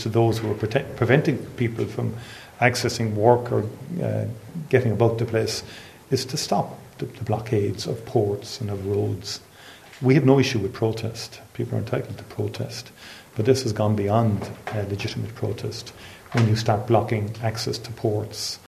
Micheál Martin made this appeal to those engaging in action: